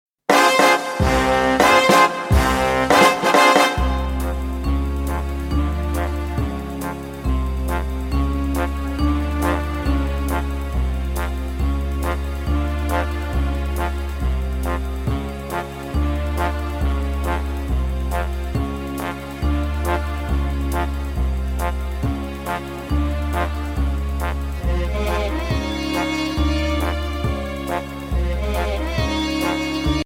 Voicing: Alto Saxophone w/ Audio